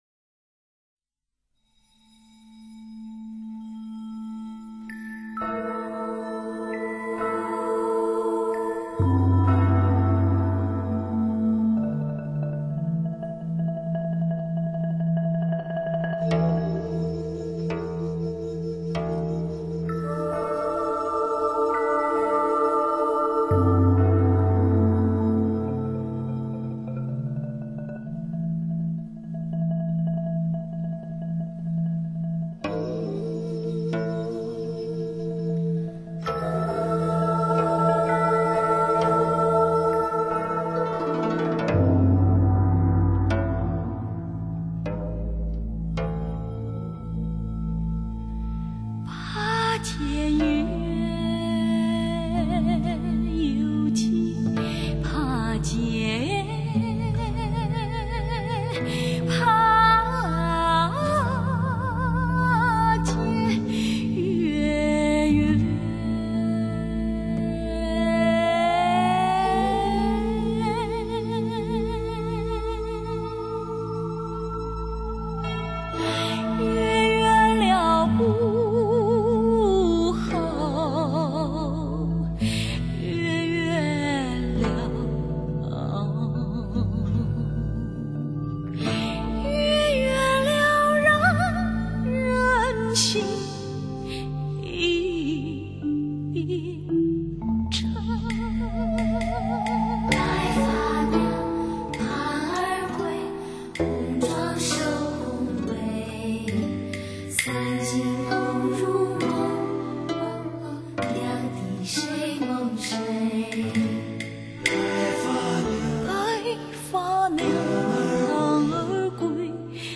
此碟音效极好。
七个段落组成，整部作品气势磅礴，演唱、录音俱佳。
呼吼，伴着吹管乐悲凉的嘶吟，是那北海湖边的冬天，让人感受到边
女声带着叙事性的低吟唱出悠悠的草原牧歌。